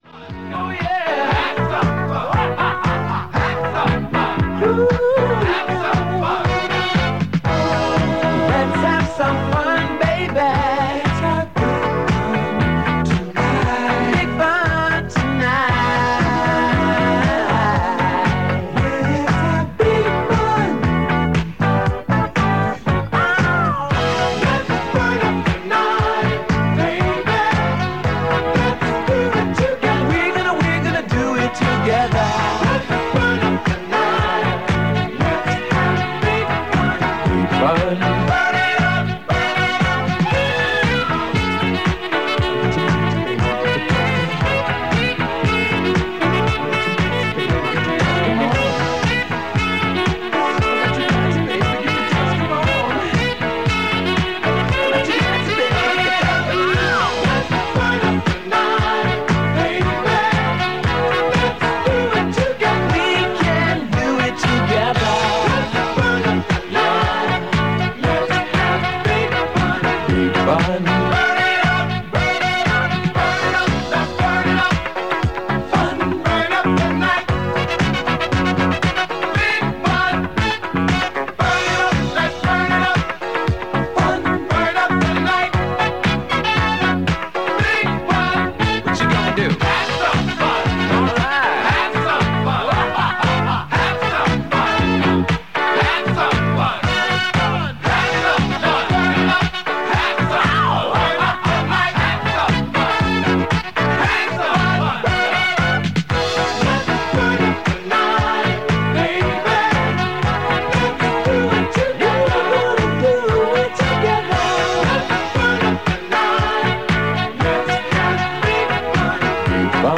This recording from 1982 features part of Good Day Sunshine with the usual mix of music and chat. A commercial break includes a promo for the Sunshine Radio Roadshow in a Dublin nightclub that weekend and there’s also a weather check at half past the hour.
This recording is airchecked and was made after 3pm on 29th August 1982 from 100.5 FM.